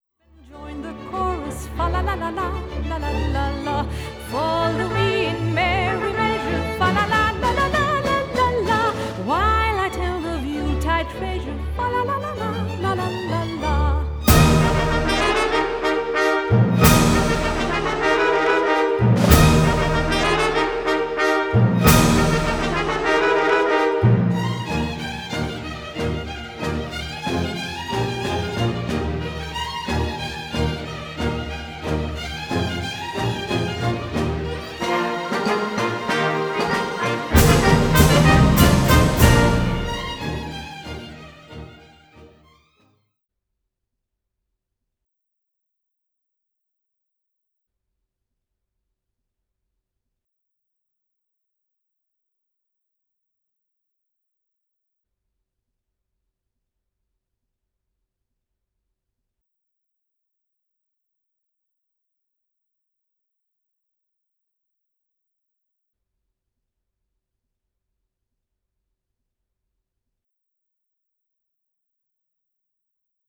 Först kommer ett avsnitt med den aktuella musiken med normalt bitdjup och nivå, sedan kommer de omväxlande partierna med musiken sänkt 90 dB och lokalstörningen med normal avspelningsnivå. Med denna "normala" avspelning lär man inte höra mycket av musiken som är sänkt med 90 dB (men den finns där, under CD-formatets teoretiska -96 dBFS nivå!) och jag tycker inte att lokalens störnivå är anmärkningsvärd (trots att jag gärna hade önskat en tystare inspelningslokal).
Musiksignalen som först är sänkt 90 dB och sedan bitreducerats till 16 bitar har konverterats med just en sådan "psykoakustisk" variant av noiseshaping/dithering som bilden ger inspiration till, den aktuella kurvan för spektral omfördelning av kvantiseringsfelen används t.ex. i Weiss' hård- och mjukvara (och Weiss anses veta vad dom pysslar med).
Ej plus 60dB.wav